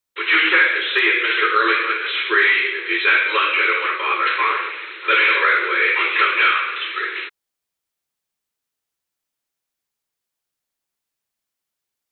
Secret White House Tapes
Conversation No. 894-8
Location: Oval Office
The President met with an unknown man.